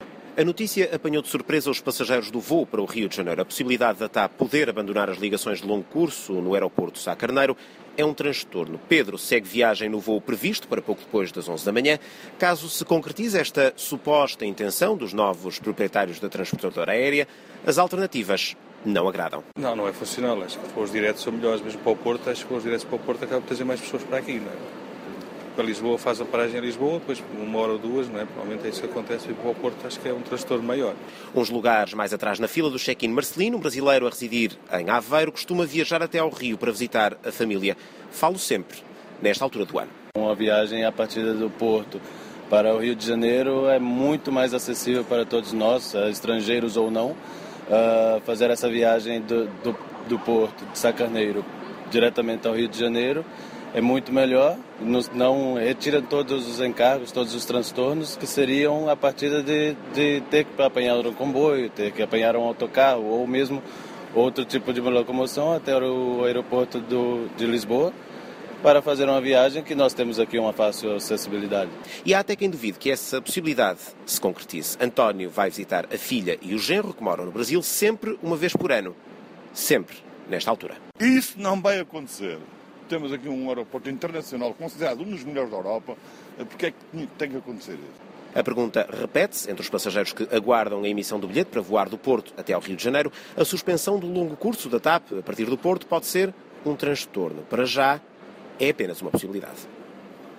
A generalidade dos utilizadores do Aeroporto Francisco Sá Carneiro, do Porto, ouvidos na manhã desta quarta-feira pela Renascença critica a possibilidade de a TAP vir a suspender os voos de longo curso, mostrando surpresa pelo facto de a hipótese ser admitida.
A reportagem da Renascença no Sá Carneiro